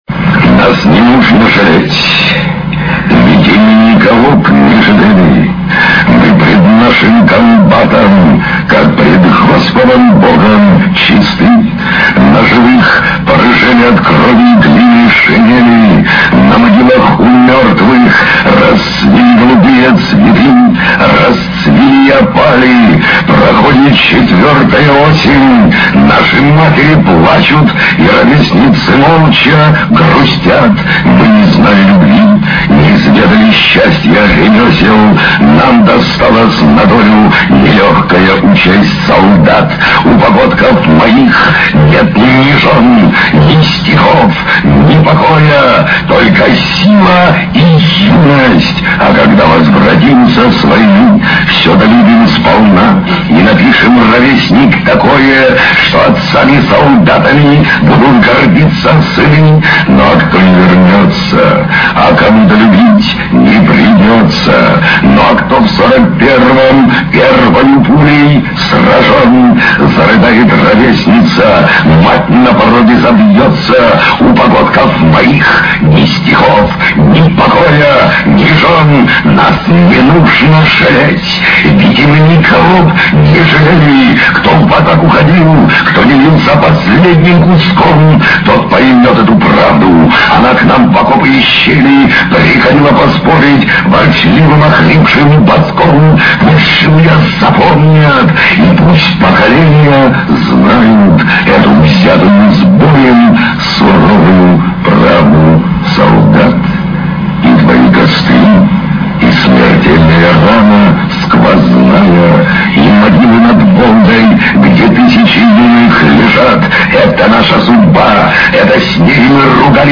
Вот как звучит «Моё поколение» в исполнении Владимира Высоцкого. Запись очень неважного качества и сделана она, по-видимому, непосредственно в зале (скачать):